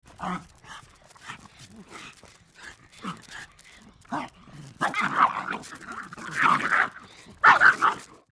Звуки мопса
Звук мопса: лай и бег в игре